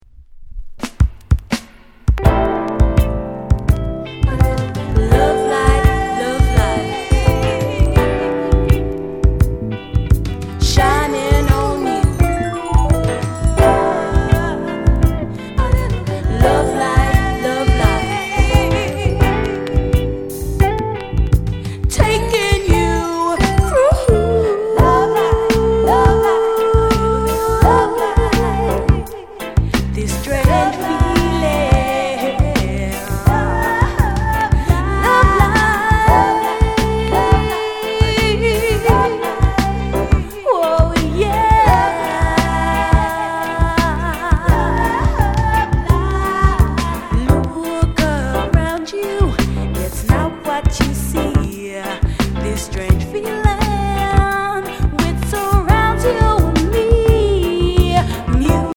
�٤��ʽ��ܤ��ܤ�����ޤ��� SLIGHT WARP ������ RARE LOVERS ROCK